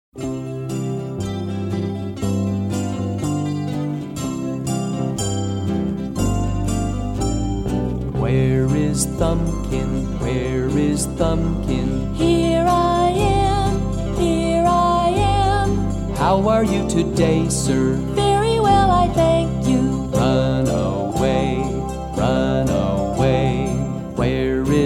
Traditional Children's Fingerplay Song with Actions